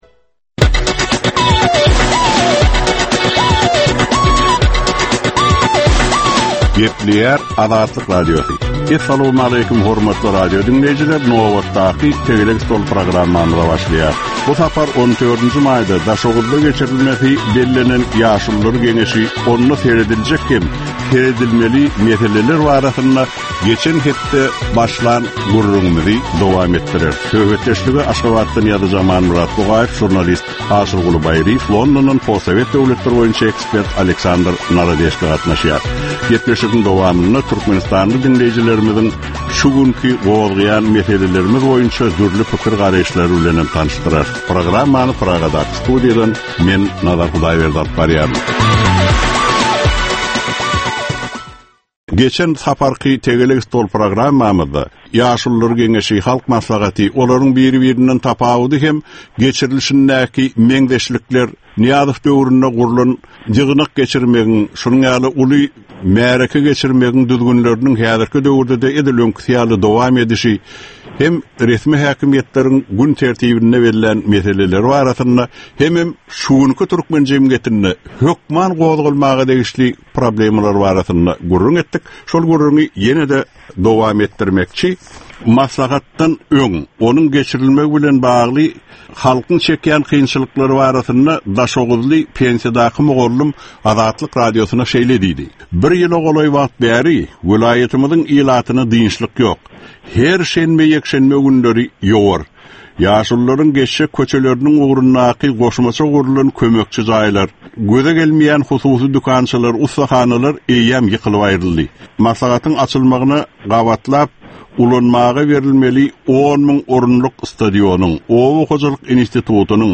Jemgyýetçilik durmuşynda bolan ýa-da bolup duran soňky möhum wakalara ýa-da problemalara bagyşlanylyp taýýarlanylýan ýörite diskussiýa. 30 minutlyk bu gepleşikde syýasatçylar, analitikler we synçylar anyk meseleler boýunça öz garaýyşlaryny we tekliplerini orta atýarlar.